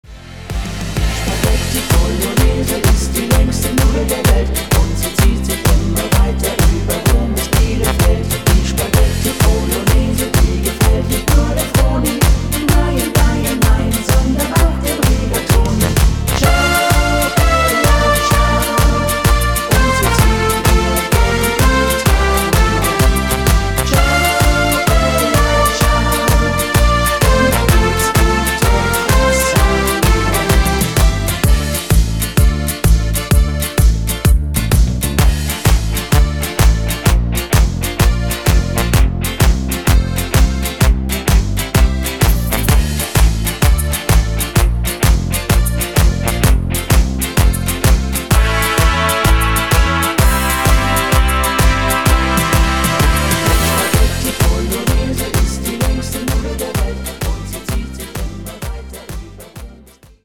hier geht es richtig ab